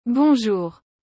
A tradução direta e sua pronúncia correta
Algo como bon-jurr, com aquele “r” no final sendo bem puxado da garganta, do jeitinho francês mesmo.
bonjour.mp3